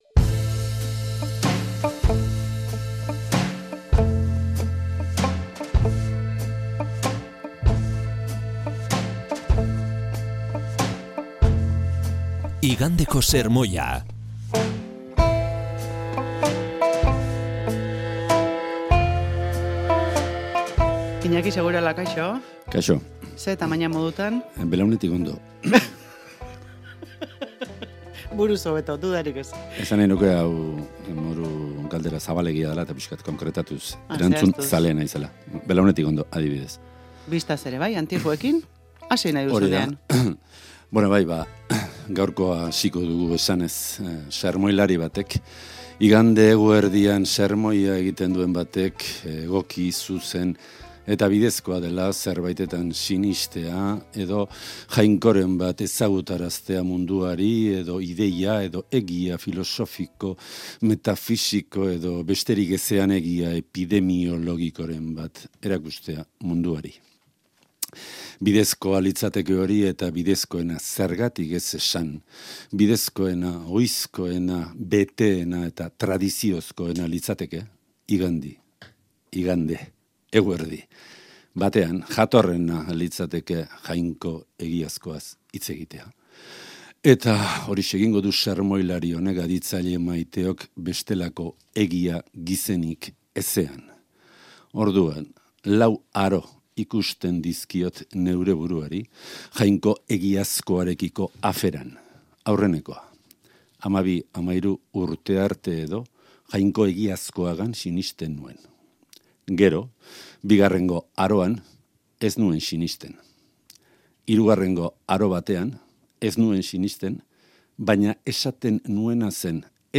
igandeko sermoia